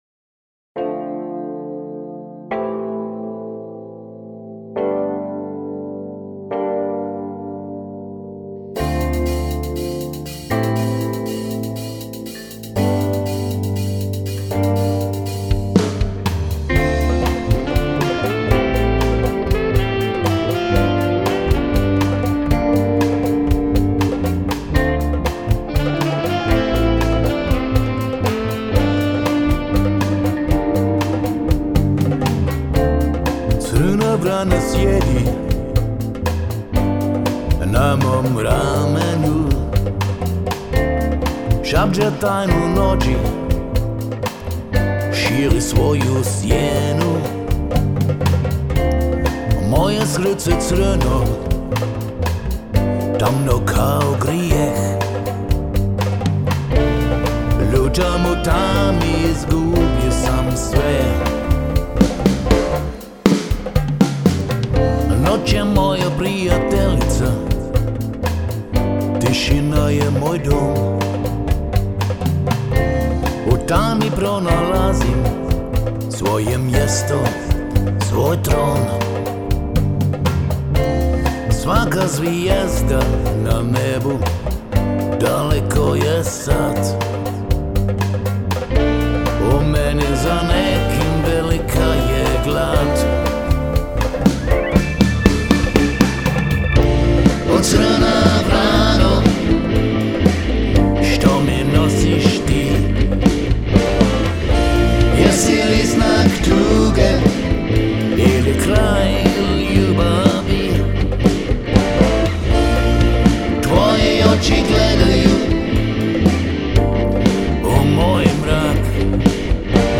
Hier findest meine Vocal-Kompositionen.